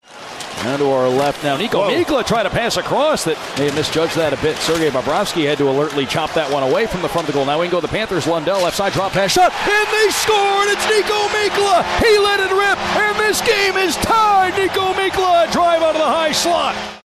PBP-Panthers-1-1-Mikkola-Goal.mp3